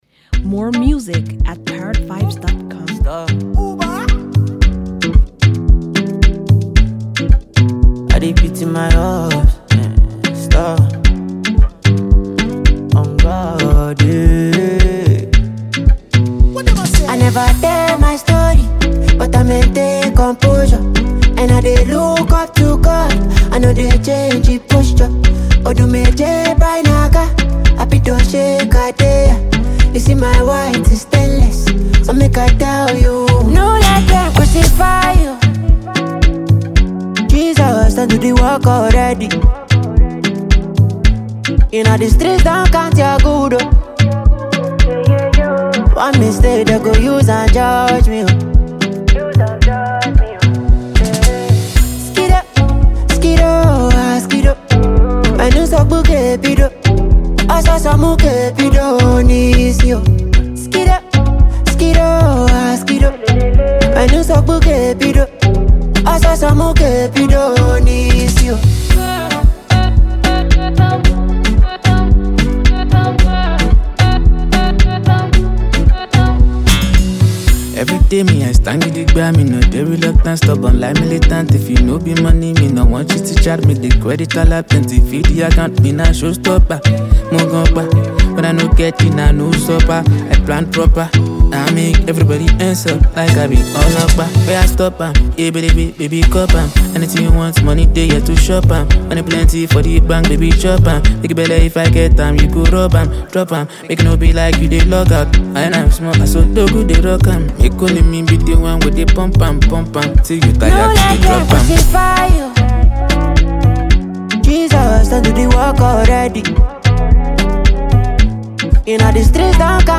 pulsating song